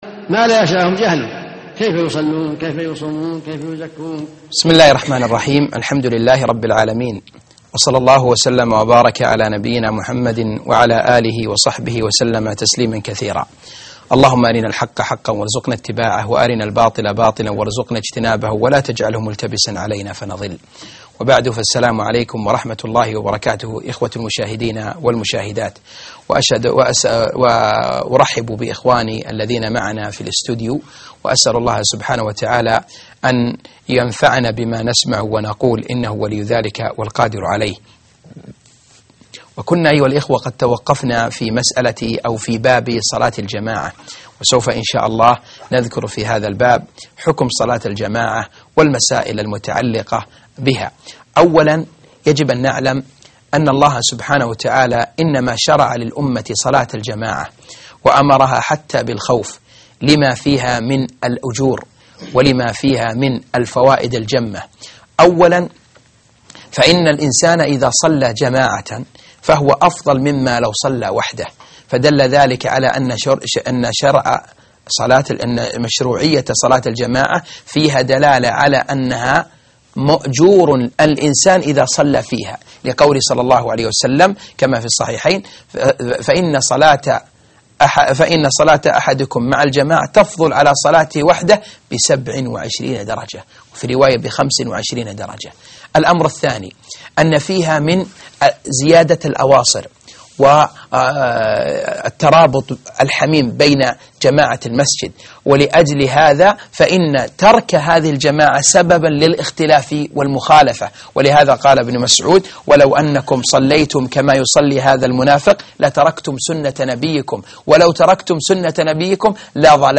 الدرس 41 _ حكم صلاة الجماعة والمسائل المتعلقة بها